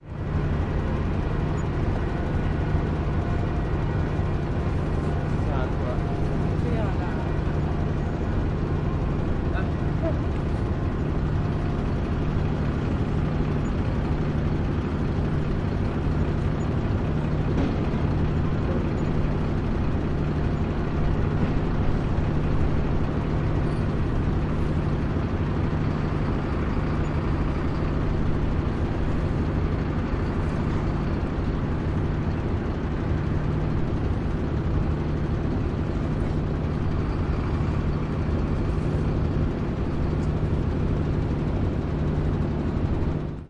描述：好的老阿尔斯特汽船的老柴油机
Tag: 柴油 发动机 电机 阿尔斯特 船舶 汉堡